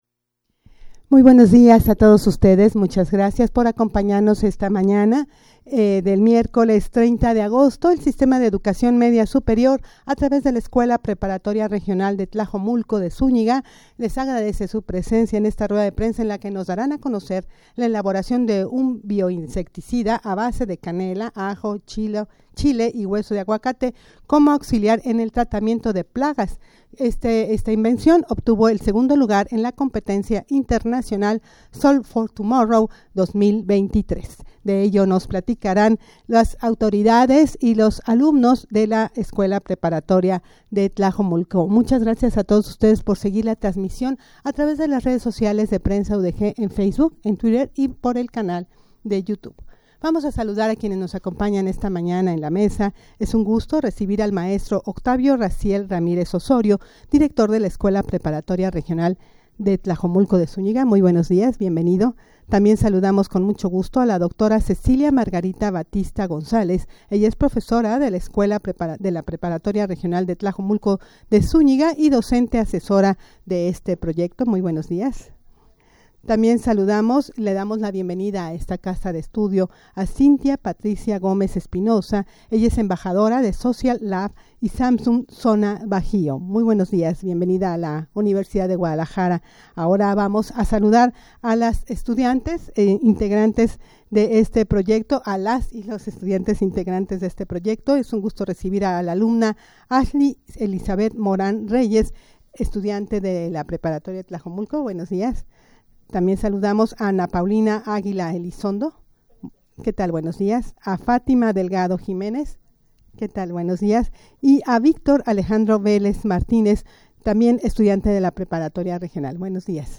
Audio de la Rueda de Prensa
rueda-de-prensa-para-dar-a-conocer-la-elaboracion-de-un-bio-insecticida-a-base-de-canela.mp3